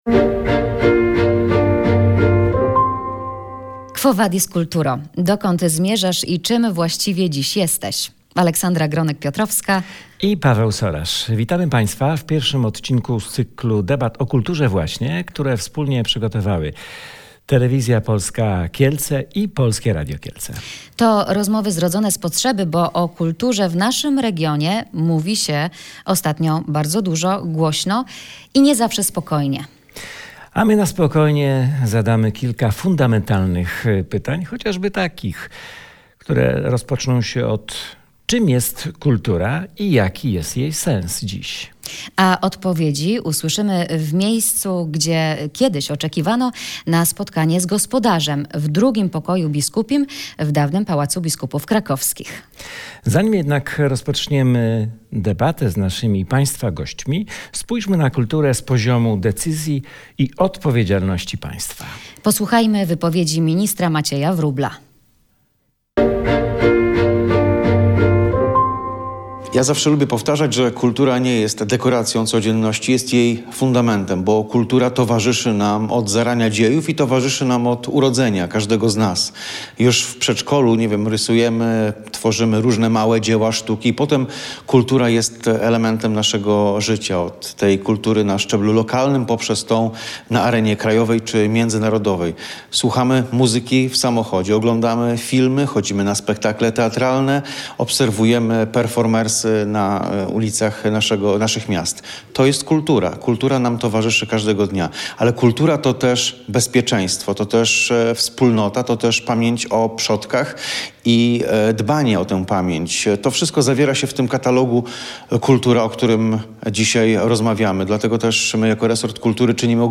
Dzisiaj pojęcie kultura, ma wiele płaszczyzn i dotyczy każdej części naszego życia. Do takiego wniosku doszli goście pierwszej wspólnej debaty „Quo Vadis Kulturo” przygotowanej przez Radio Kielce i TVP3 Kielce.